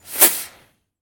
rocketaim.ogg